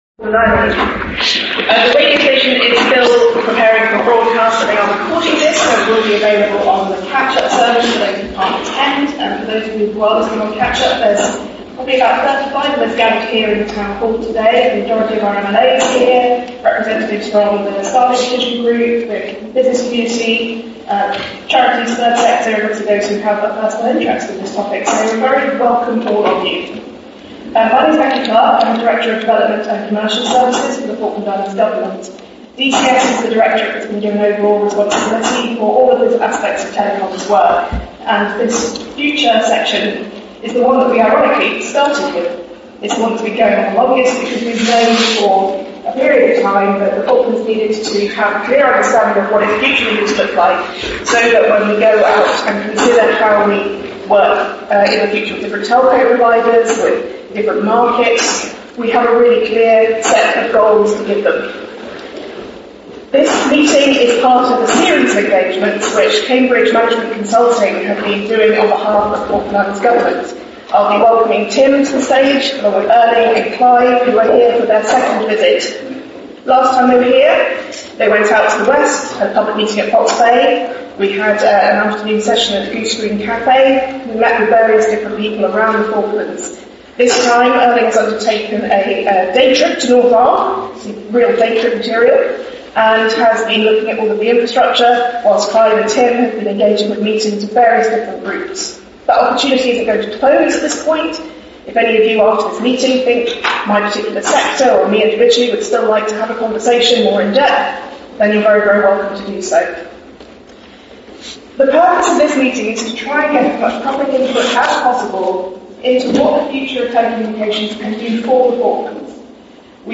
There were 30 to 40 attendees.
The audio quality of this recording is not brilliant, but it is complete. It was a recording made by an attendee on their mobile phone.